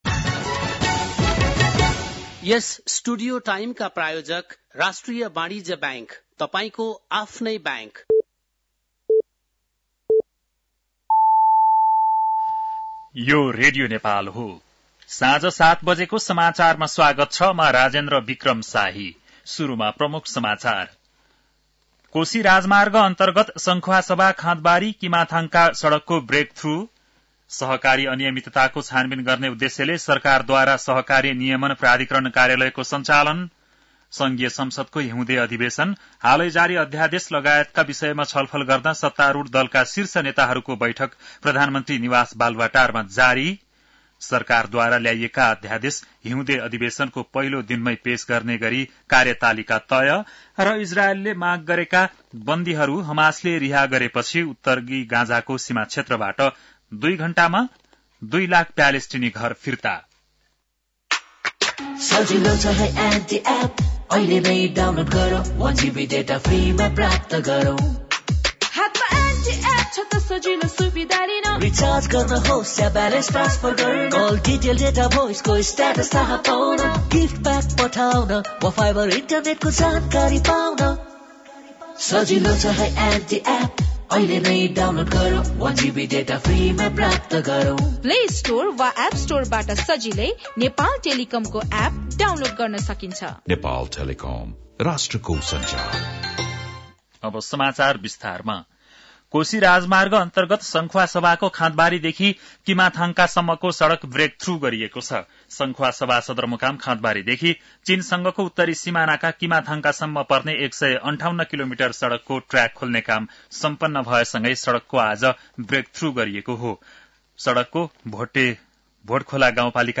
बेलुकी ७ बजेको नेपाली समाचार : १५ माघ , २०८१
7-pm-nepali-news-10-14.mp3